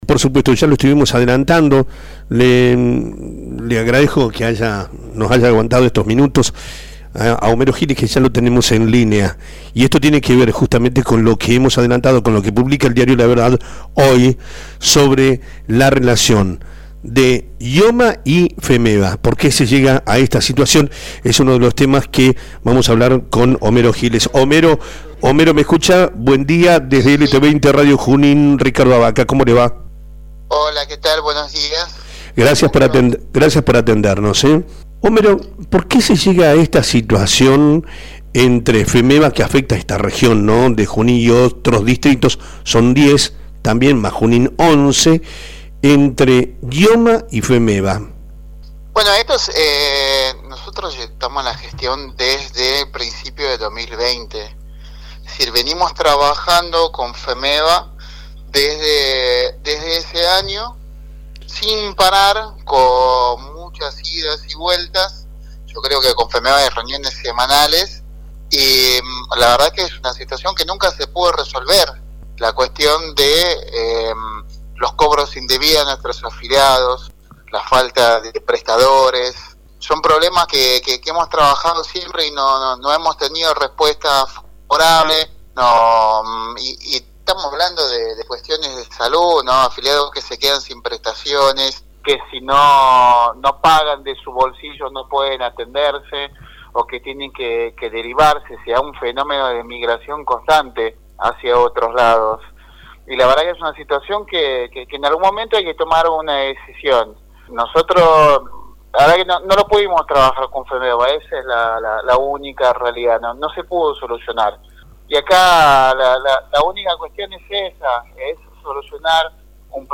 El presidente de IOMA, Homero Giles, habló en el programa “Amanece que no es poco” (LT 20 Radio Junín AM 1470) acerca de la decisión del Directorio de la obra social de prescindir de los servicios de FEMEBA para el partido de Junín a partir del 1º de septiembre, en virtud de casos denunciados sobre la calidad de cobertura médica a la que no acceden o acceden mediante el pago de sumas indebidas más de 80.000 afiliadas/os de la región.